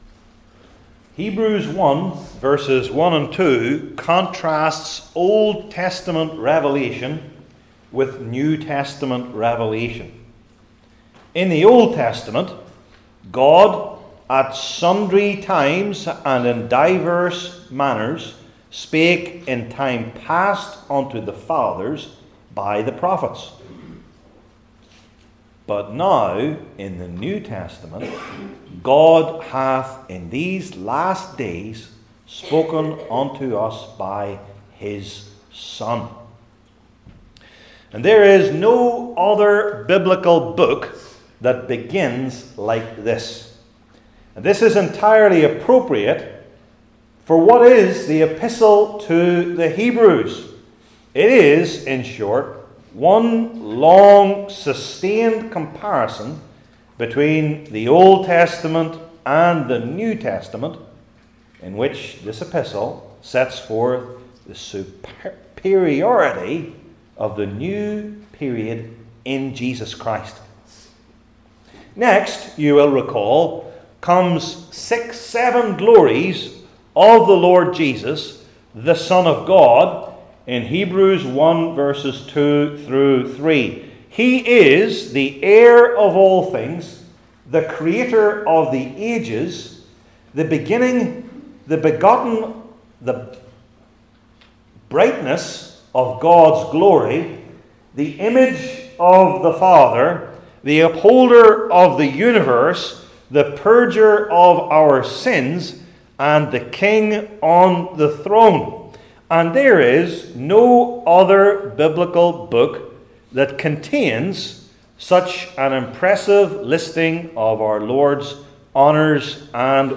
New Testament Sermon Series I. The Quotation II.